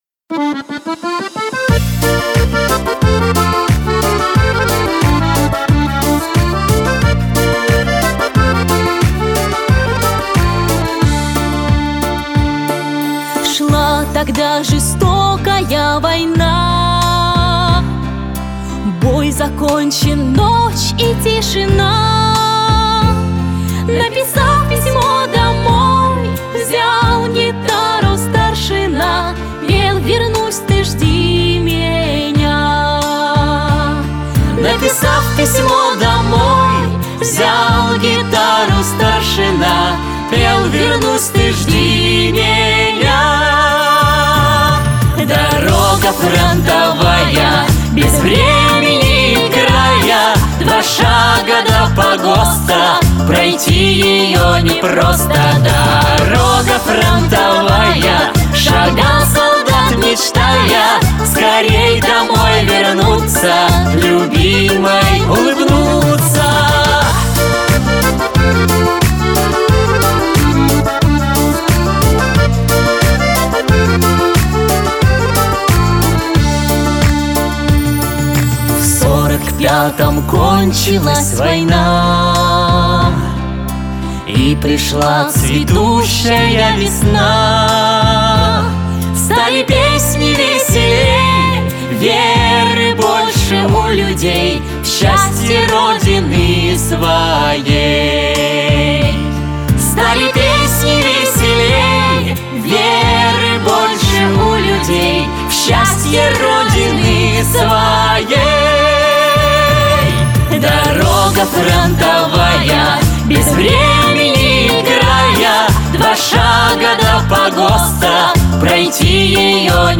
• Качество: Хорошее
• Жанр: Детские песни
военные песни